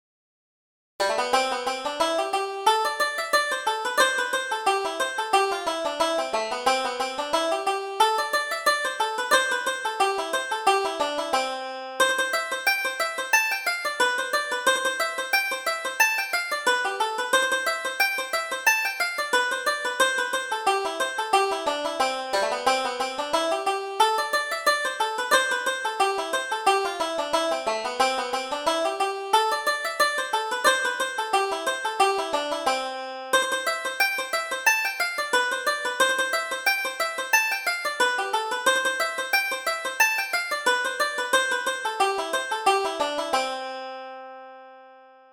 Reel: Carrigaline